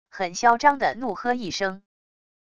很嚣张的怒喝一声wav音频